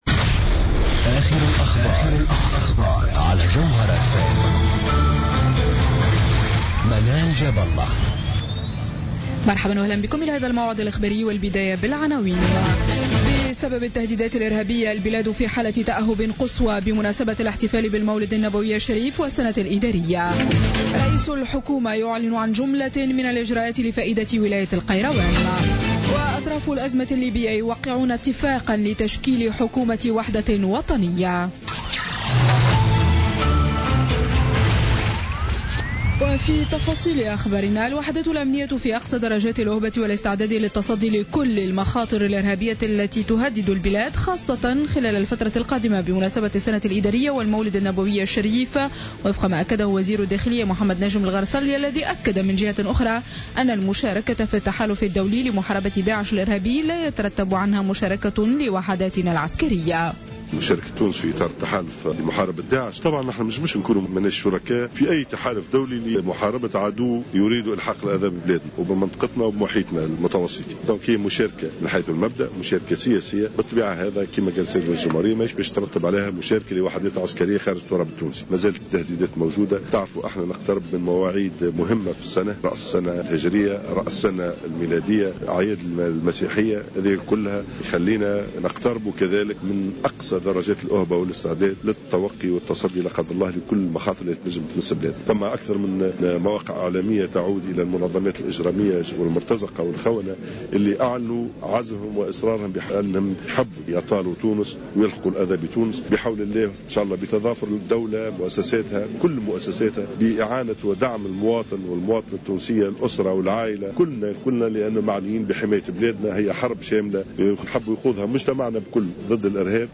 نشرة أخبار منتصف الليل ليوم الجمعة 18 ديسمبر 2015